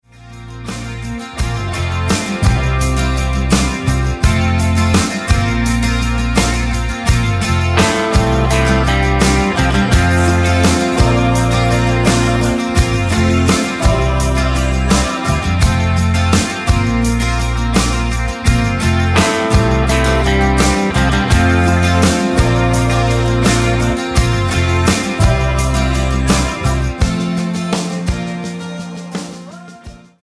--> MP3 Demo abspielen...
Tonart:F mit Chor